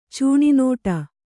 ♪ cūṇi nōṭa